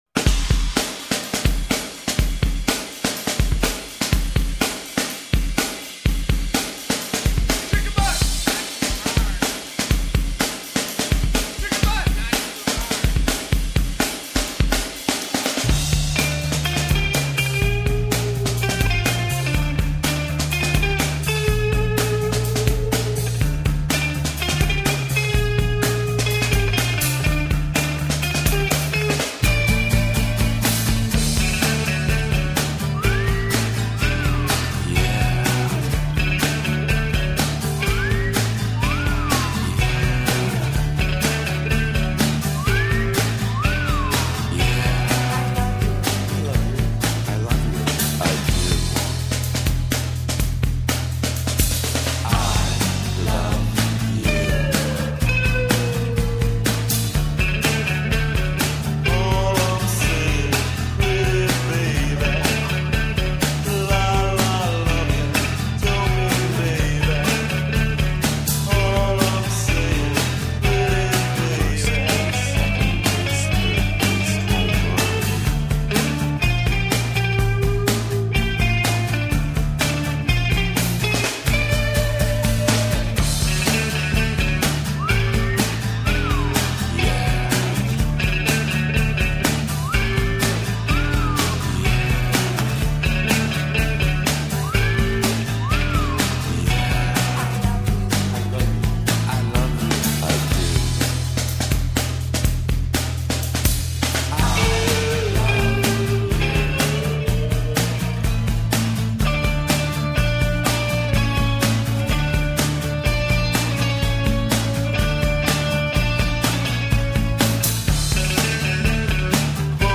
This band rocks.